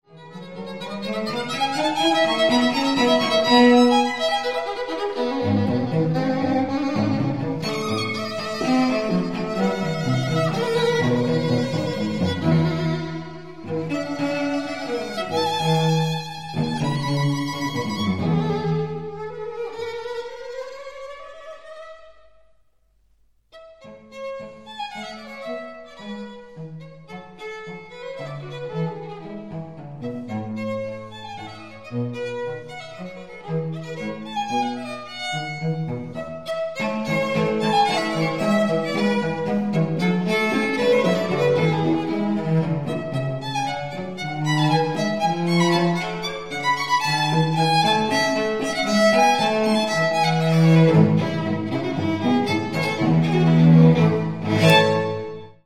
first world recording on period instruments